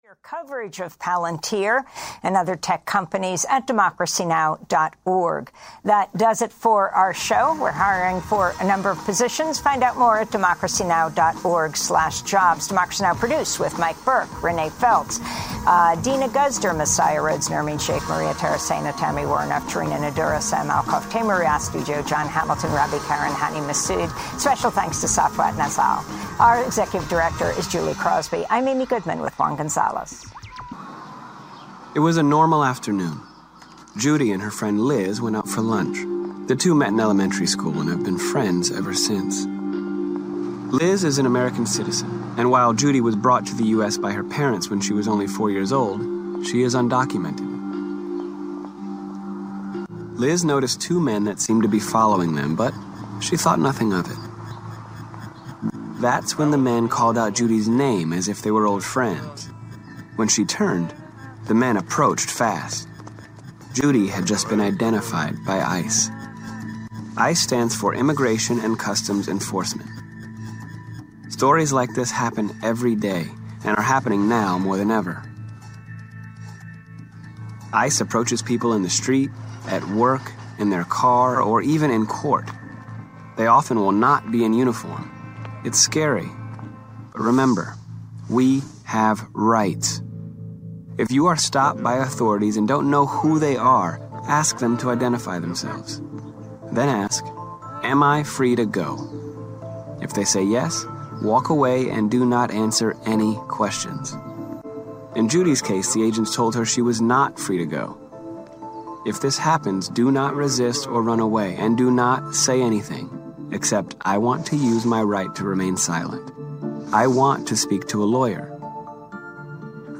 Off The Wall is a weekly show on WUSB radio, Stony Brook - Long Island, produced by 2600 Magazine.